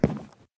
Sound / Minecraft / step / wood3